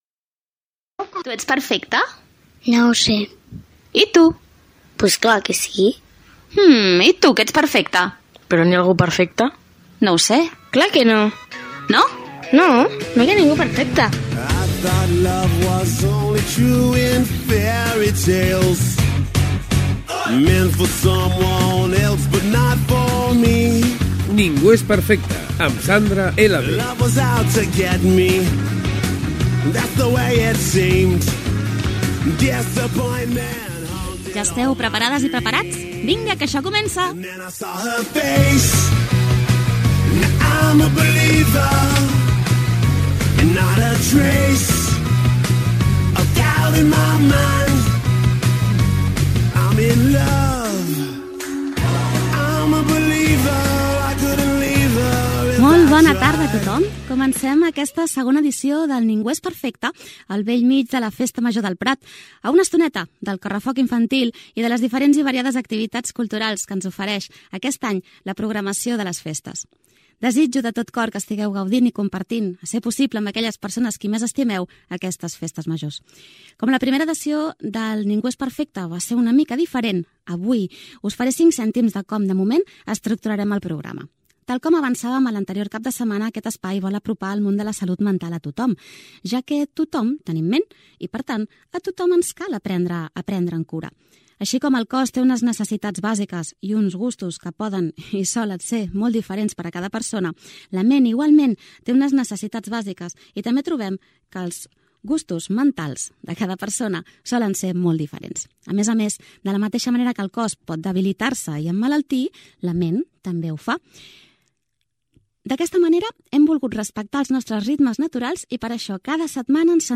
Tertúlia sobre solitud i aïllament no desitjat.